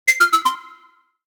notification_006.ogg